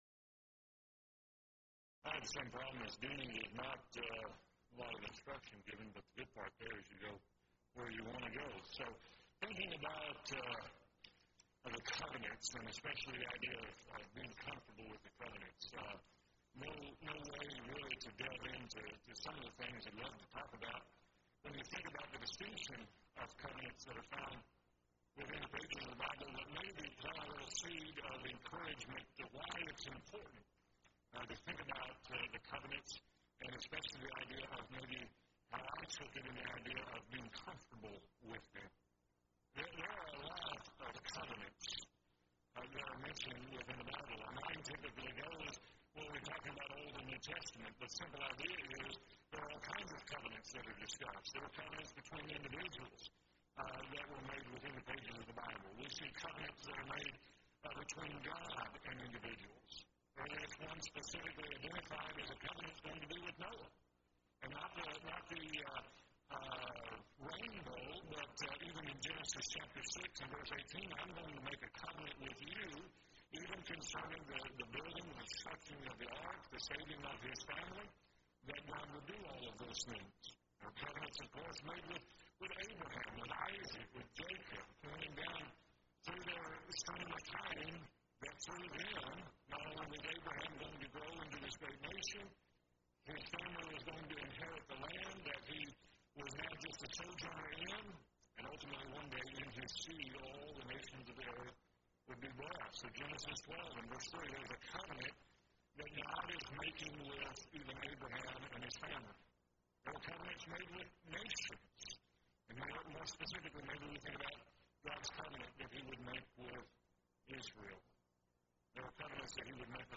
Event: 2nd Annual Young Men's Development Conference
If you would like to order audio or video copies of this lecture, please contact our office and reference asset: 2018YMDC41